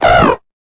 Cri_0875_EB.ogg